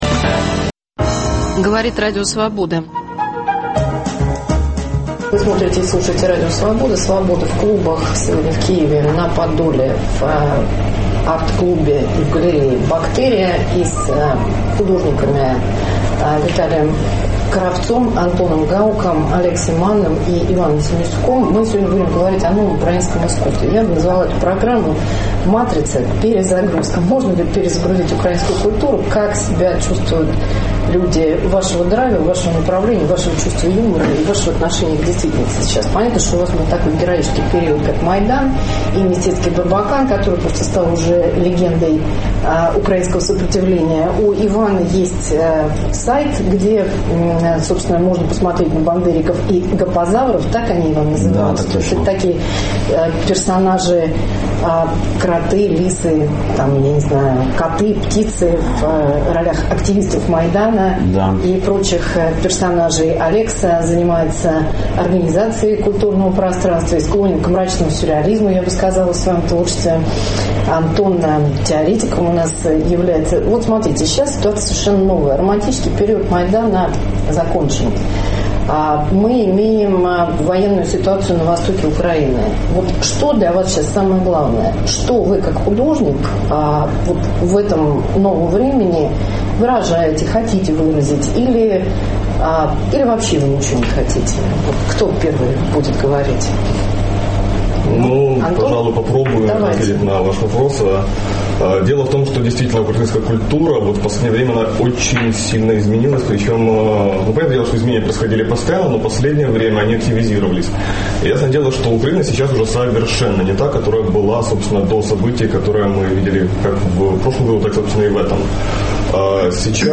Свобода в арт-клубе "Бактерия", Киев, Подол. Каким должно быть новое современное украинское искусство?